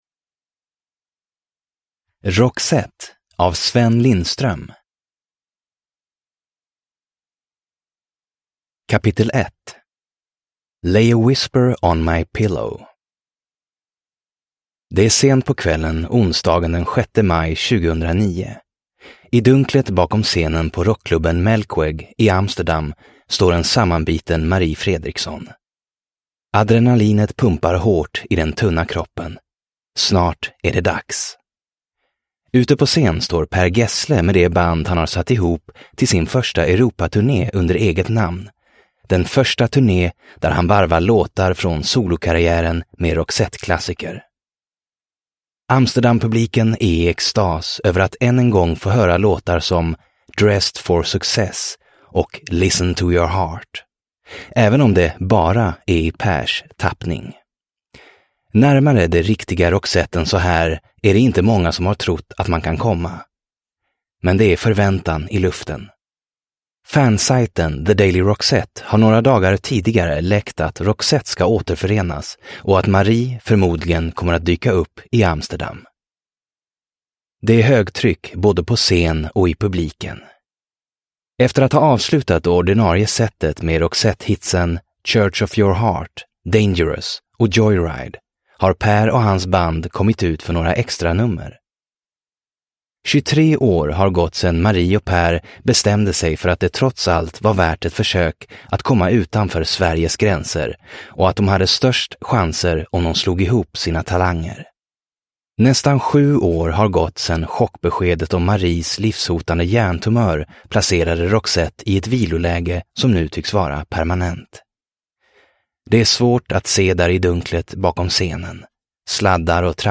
Roxette : Den osannolika resan tur och retur – Ljudbok – Laddas ner